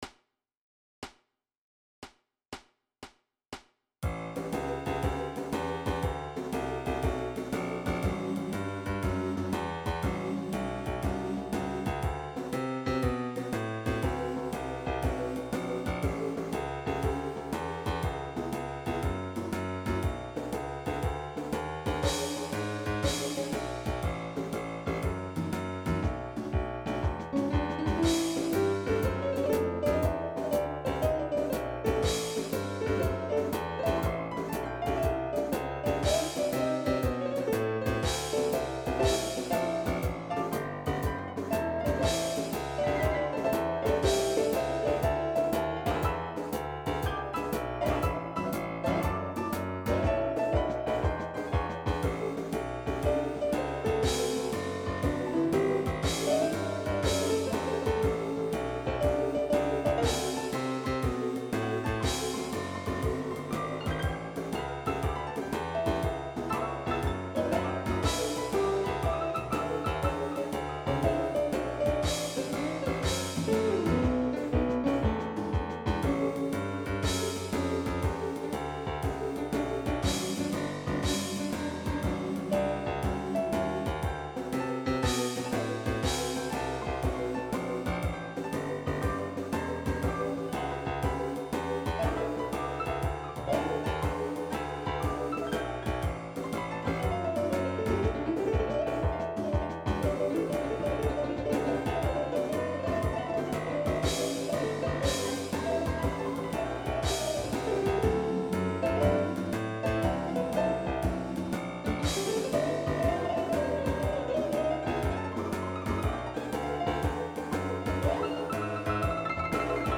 Für die Klangbeispiele habe ich das Augmented Piano und die Augmented Horns und Brass verwendet.
augmented-grand-piano.mp3